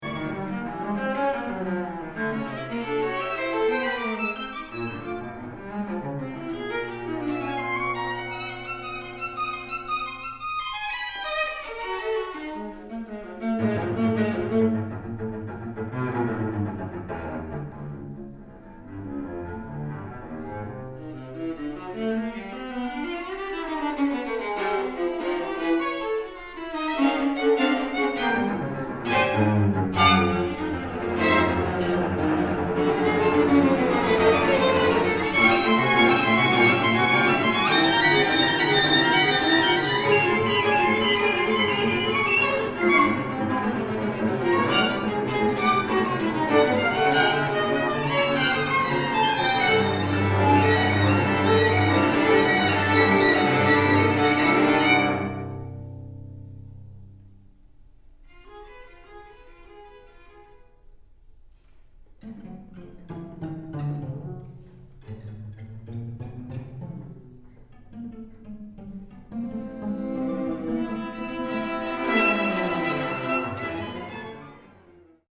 listen (mp3) near beginning (1:15) near end (0:26) Times Square for string quartet 2003, 6 minutes Outwardly, Times Square is about how busy the station is, but inwardly, it's about urban solitude, the contradiction between masses of humanity around you, and no one to talk to or connect with meaningfully. The arc of the piece decays from the external to the internal.